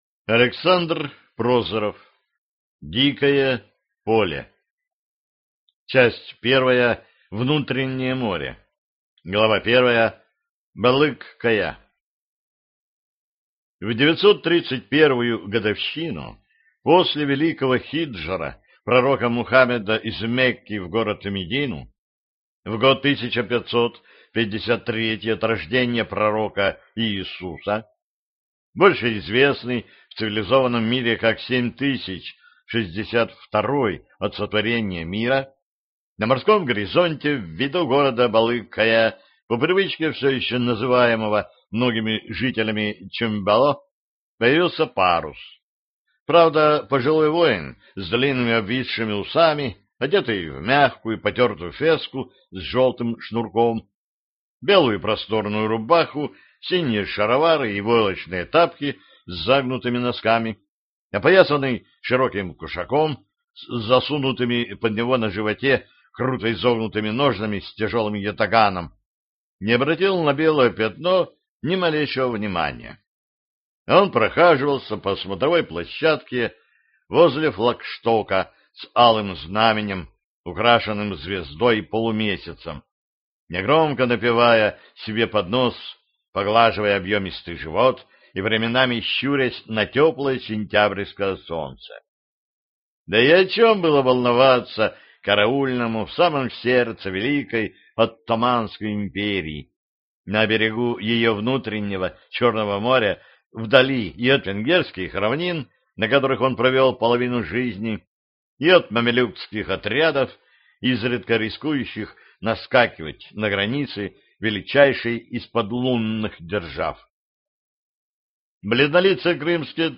Аудиокнига Дикое поле | Библиотека аудиокниг